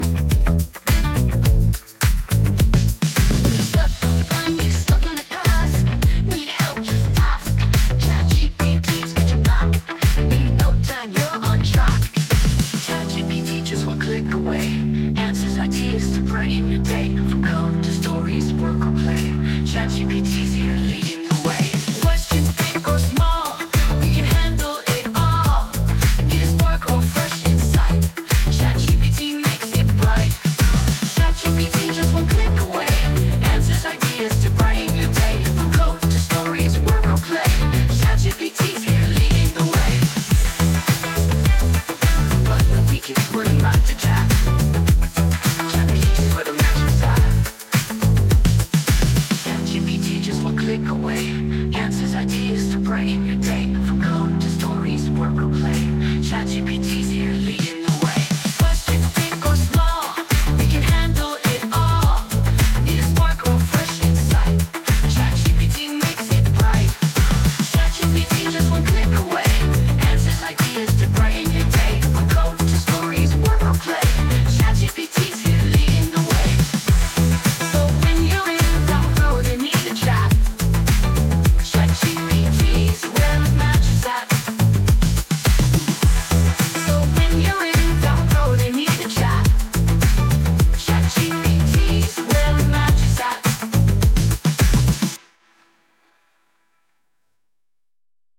Upbeat pop, Feel-good retro-pop, Groovy funk, Punchy bass